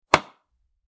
Tennis-ball-sound-effect.mp3